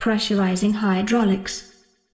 Hydrolics_on.ogg